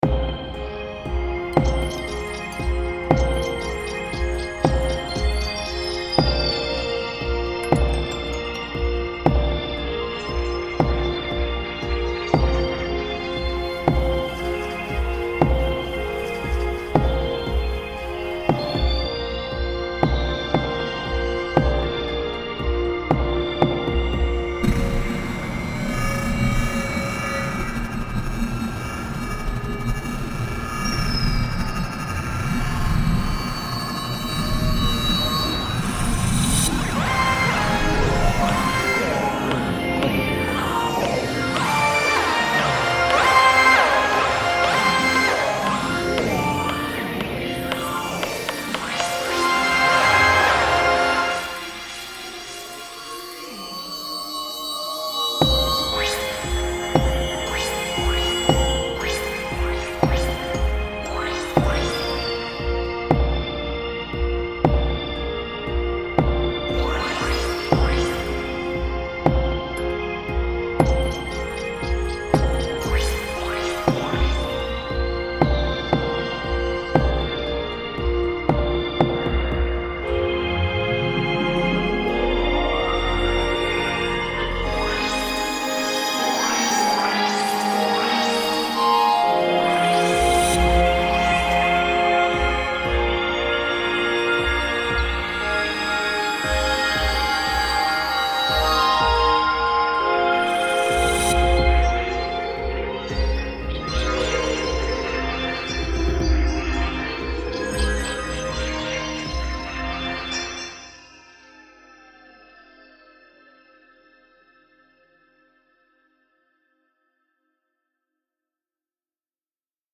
The Wizard II is full of musically useful atmospheric textures and special effects presents a vital tool for TV, Film, Game, and Music Production. Crunchy noises, long atmospheres that build over time, and weird percussion that Doppler pans around your head.
* Atmospheres * Fright * Tension * Space * Distortion * Digital Soundscapes * Digital keys * Evolving Pads * Drones